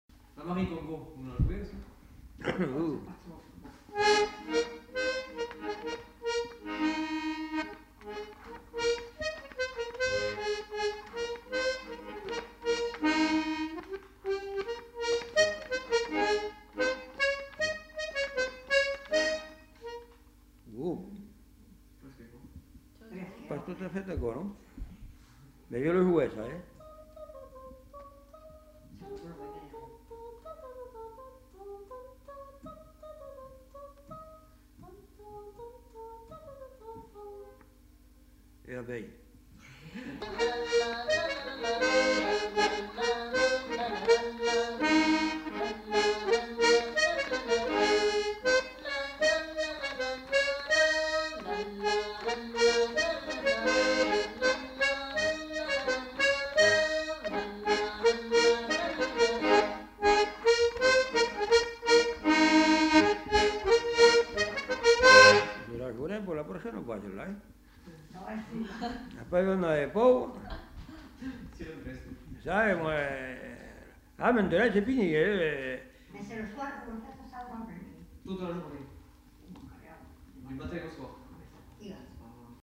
Aire culturelle : Savès
Lieu : Beaumont-de-Lomagne
Genre : morceau instrumental
Instrument de musique : accordéon diatonique
Danse : congo